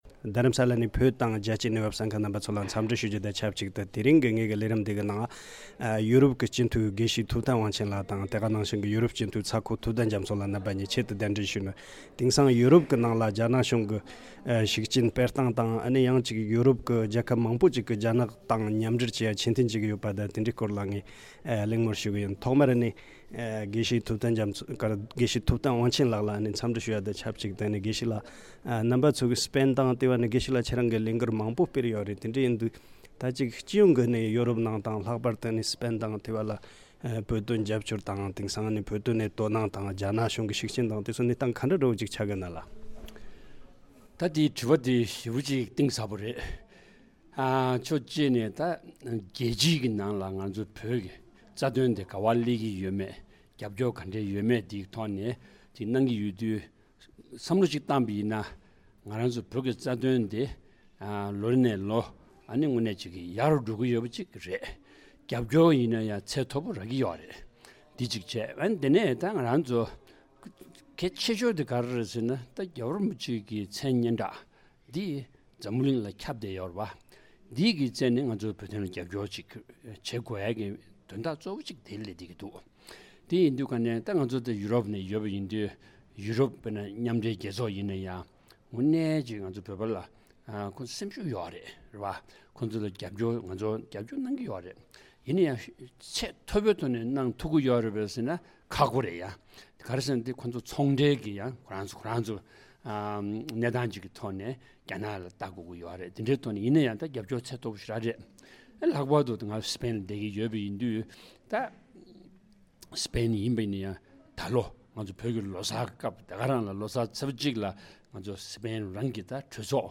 ཡོ་རོབ་ནང་བོད་དོན་རྒྱབ་སྐྱོར་གྱི་གནས་སྟངས་དང་རྒྱ་ནག་གི་ཤུགས་རྐྱེན་ཆུང་དུ་འགྲོ་བཞིན་པའི་སྐོར་ཡོ་རོབ་སྤྱི་འཐུས་རྣམ་པ་གཉིས་ལ་བཀའ་འདྲི་ཞུས་པ།